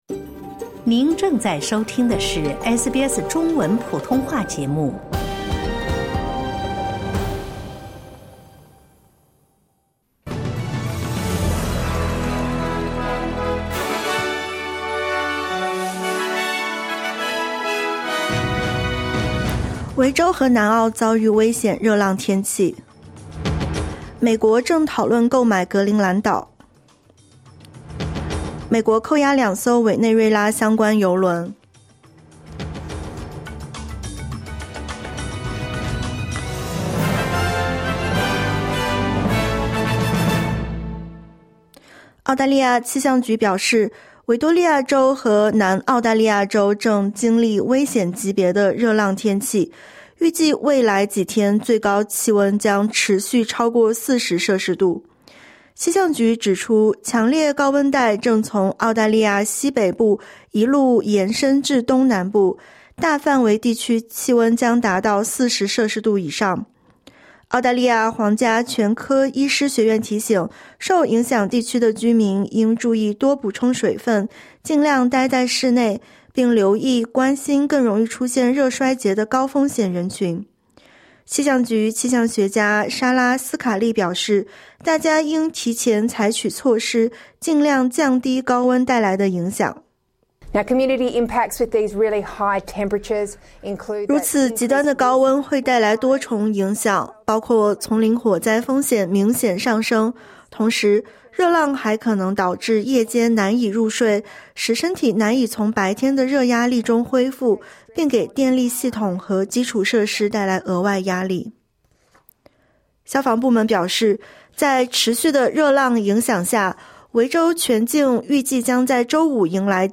【SBS早新闻】维州和南澳遭遇危险热浪天气